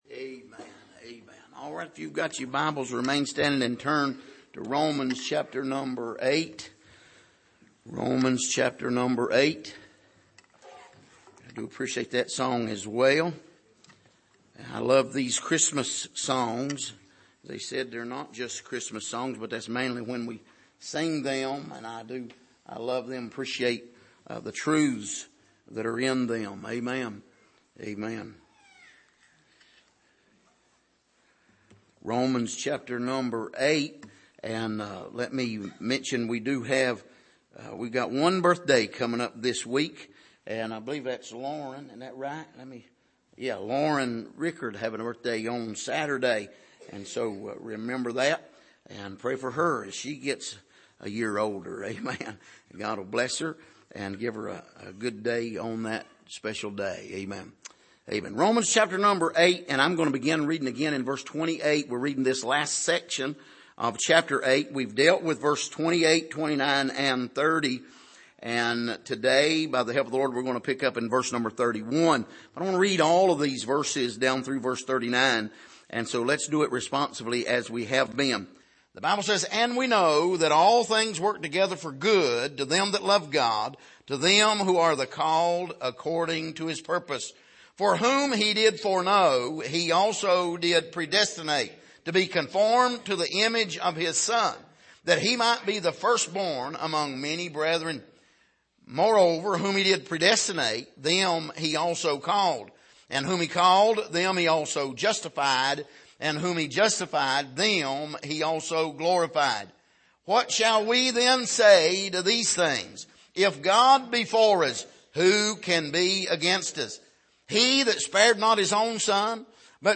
Passage: Romans8:31-39 Service: Sunday Morning